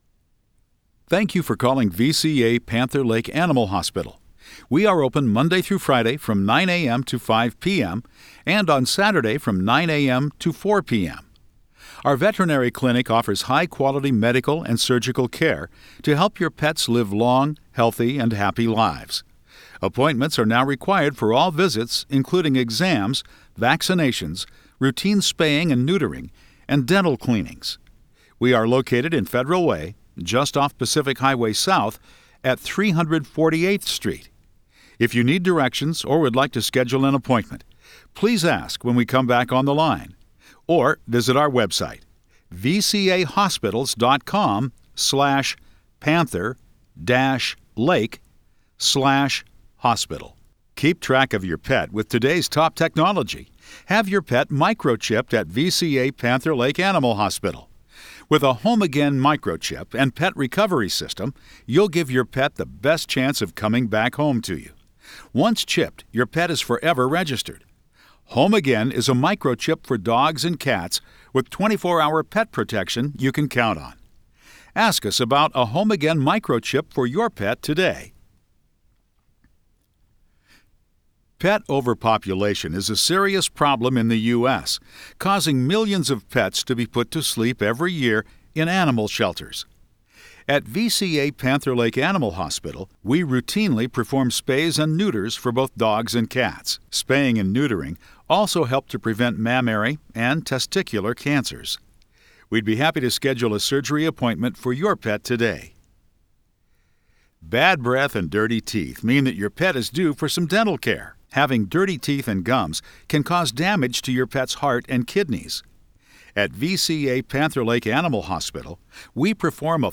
Industrial Voice-Over
I also narrate public-facing content such as automated phone systems and safety and instructional material.